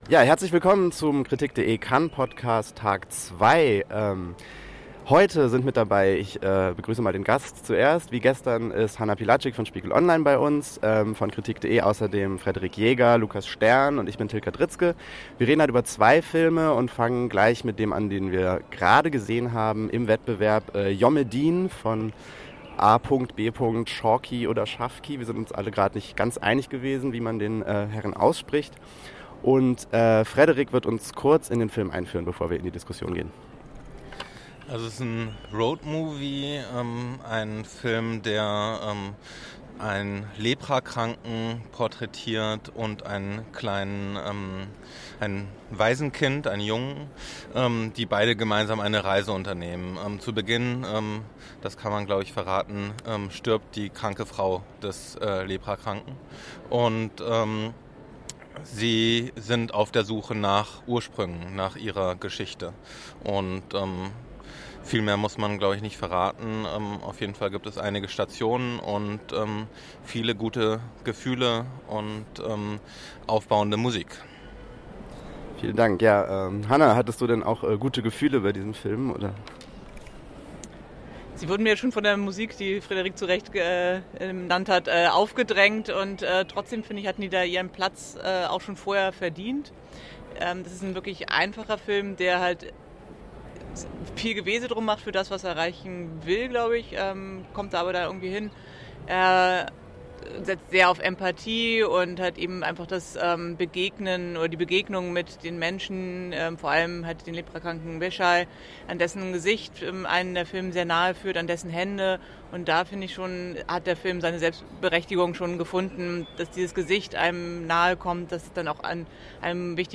Zweiter Podcast vom Festival von Cannes 2018.